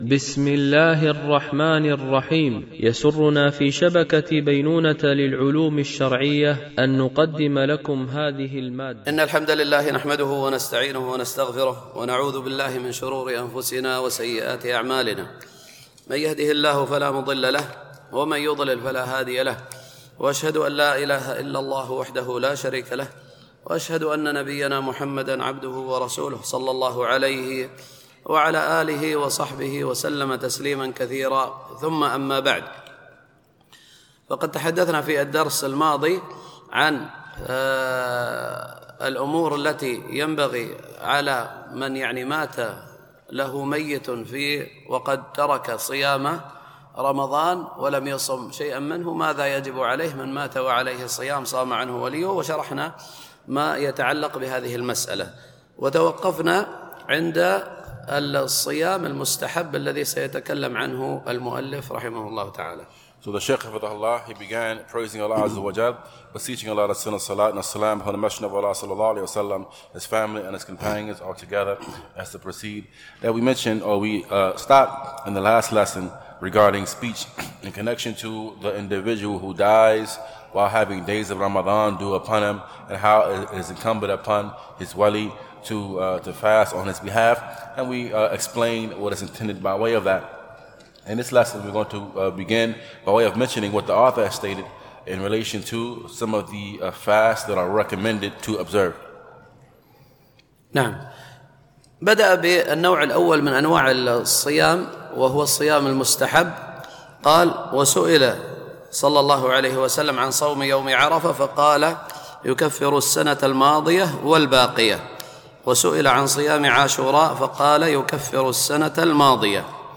دورة علمية مترجمة للغة الإنجليزية، لمجموعة من المشايخ، بمسجد أم المؤمنين عائشة رضي الله عنها